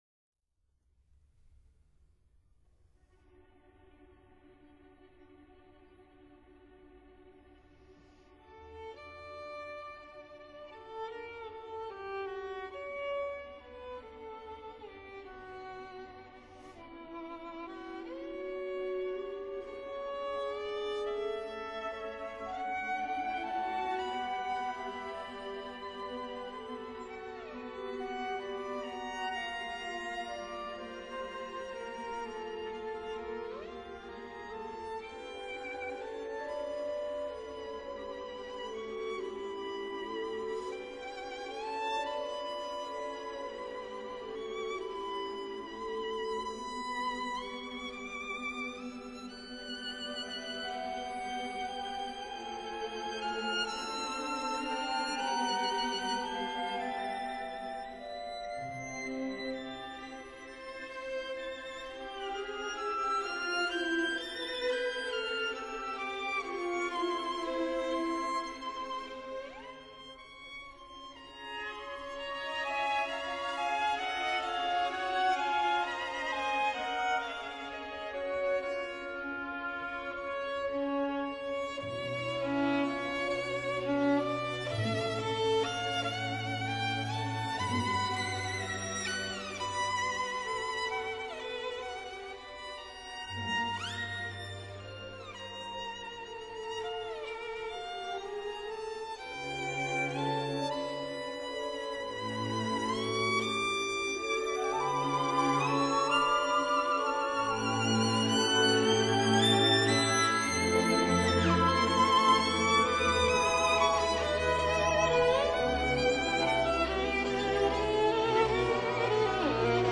Genre: Classical, violin